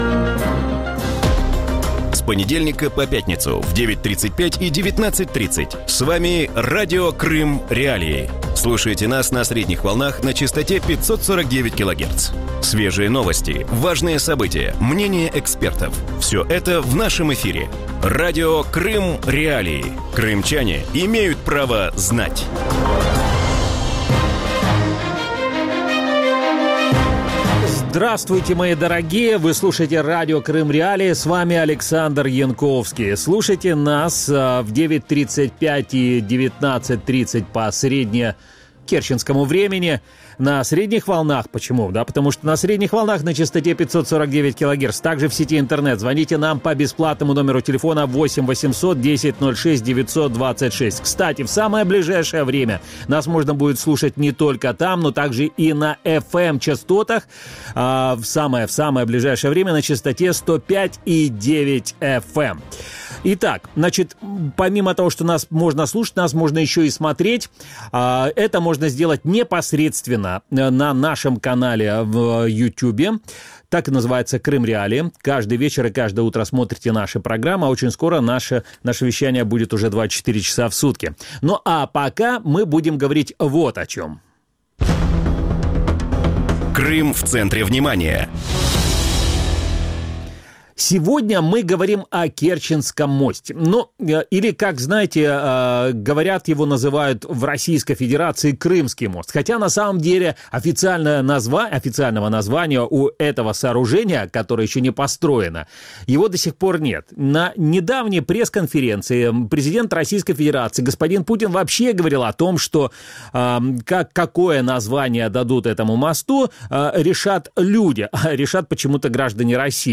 В вечернем эфире Радио Крым.Реалии обсуждают, почему в России не могут найти генерального подрядчика на строительство железной дороги в Крым. Почему российские компании отказываются прокладывать железнодорожную ветку через строящийся мост и на сколько может затянуться строительство?